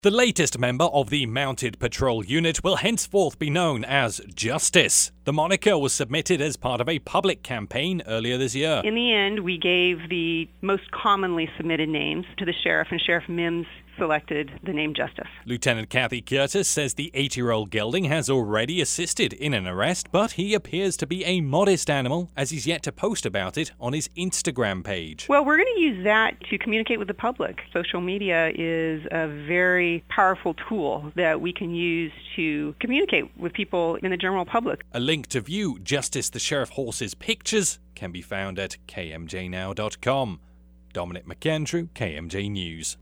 DM-HORSE-SHERIFF2.mp3